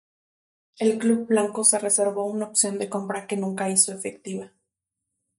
Read more Noun Verb 🛍 comprar to buy (to obtain in exchange of money or goods) to buy out Read more Frequency B2 Hyphenated as com‧pra Pronounced as (IPA) /ˈkompɾa/ Etymology Deverbal from comprar.